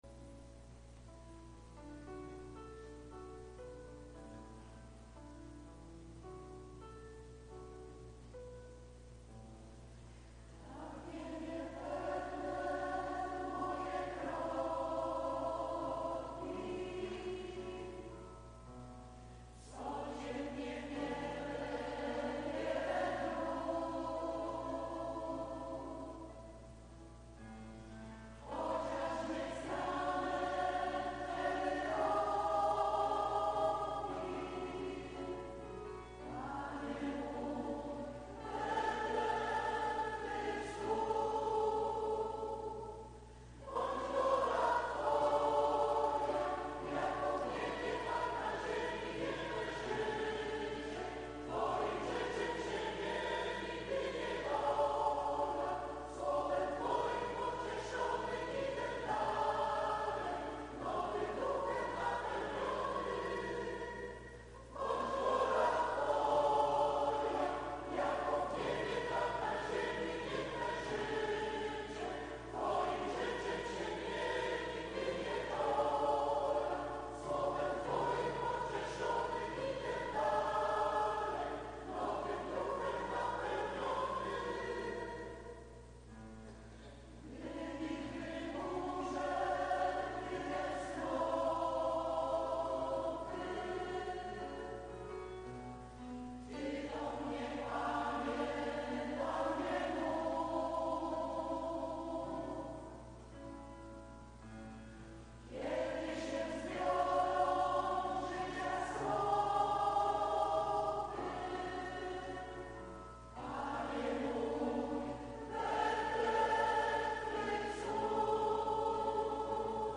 2 NIEDZIELA PO TRÓJCY ŚWIĘTEJ